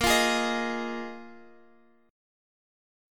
Bb7sus4 Chord